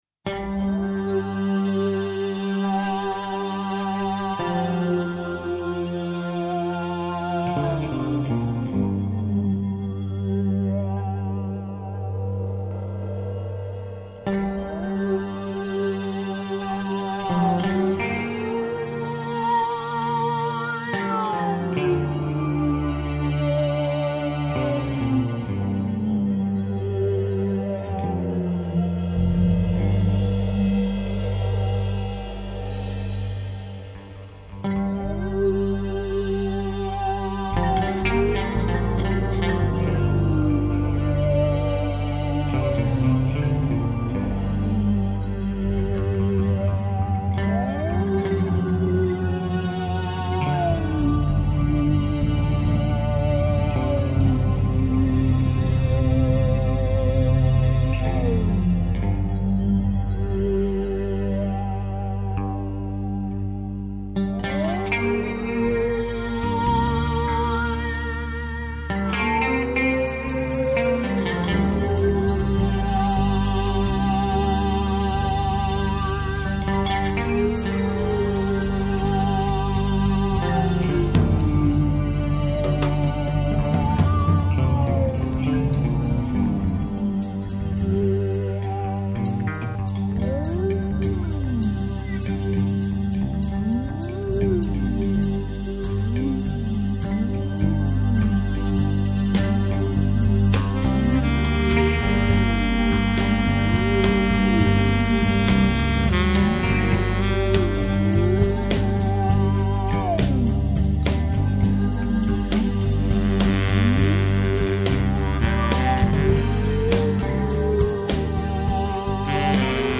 baritone sax, tarogato
bass, electric bass
drums
violin. Here two mp3 from the BBC Session.